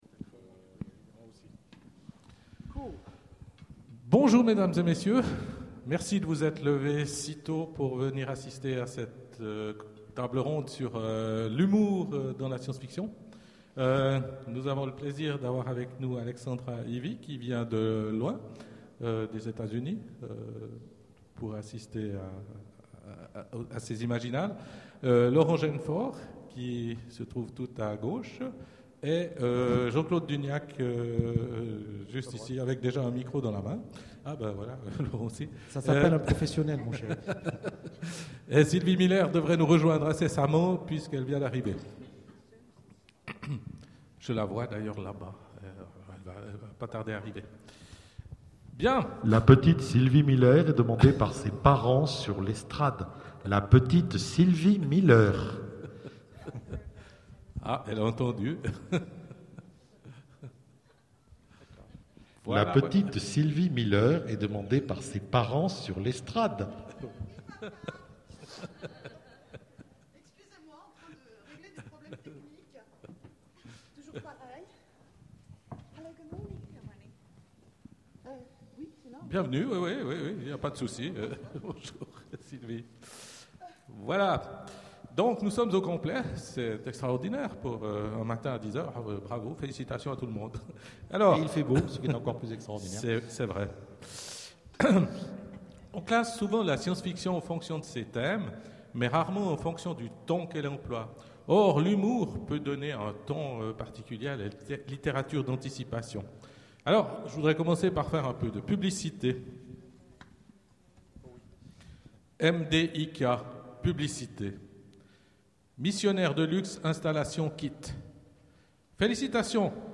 Imaginales 2013 : Conférence De l'humour dans l'imaginaire